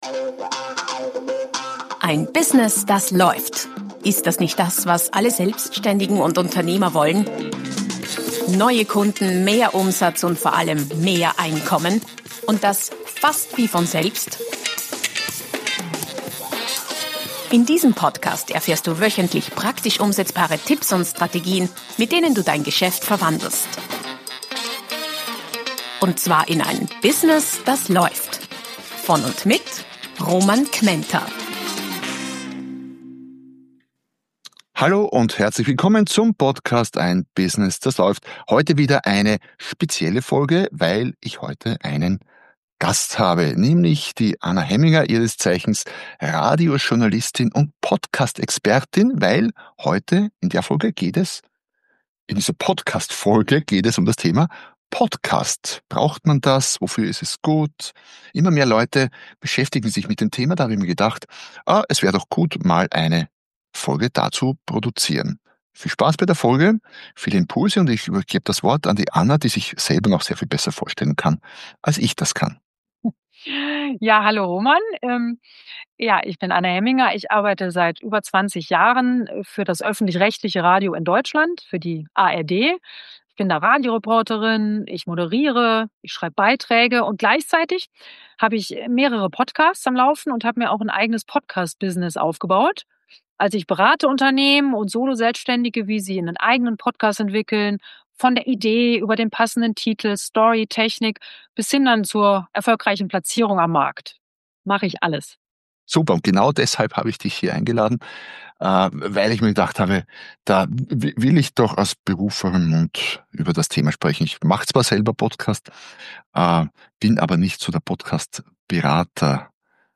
407 - Expertengespräch mit Radiojournalistin und Podcasterin